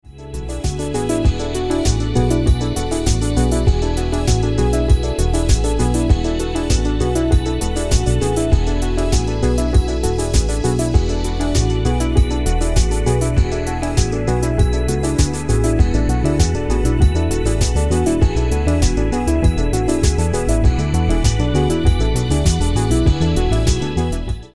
BPM: 116